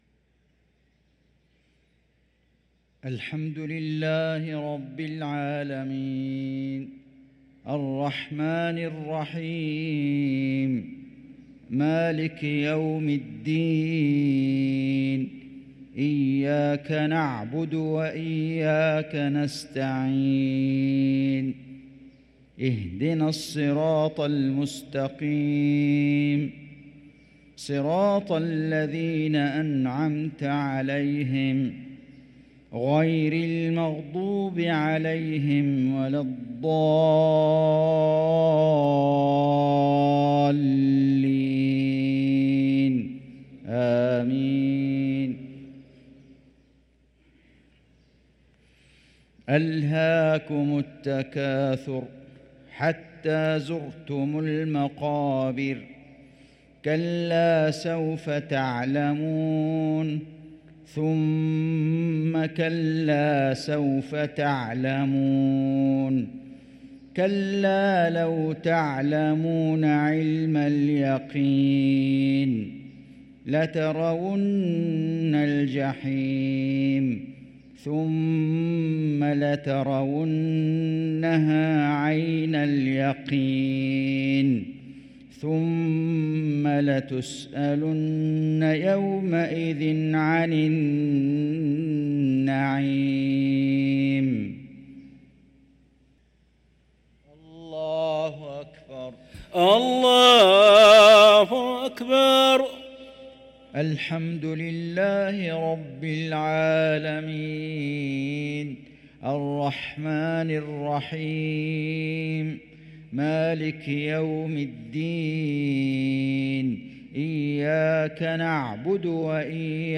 صلاة المغرب للقارئ فيصل غزاوي 12 ربيع الأول 1445 هـ
تِلَاوَات الْحَرَمَيْن .